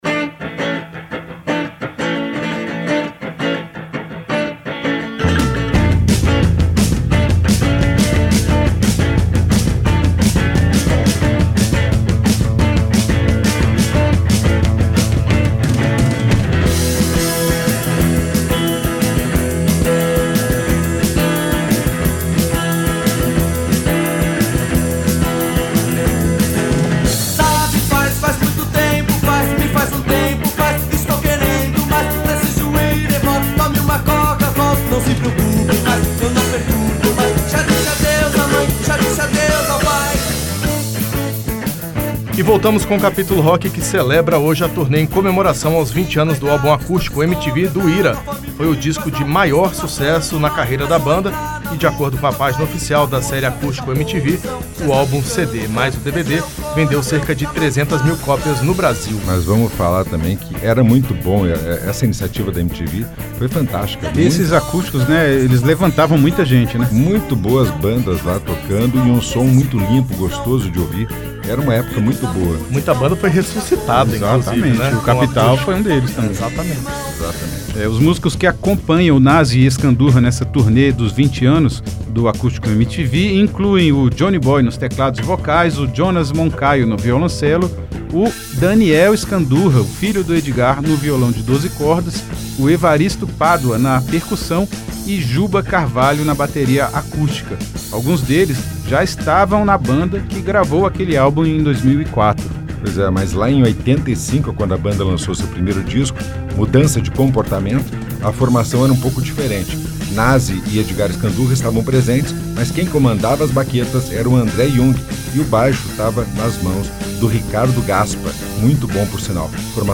arranjos acústicos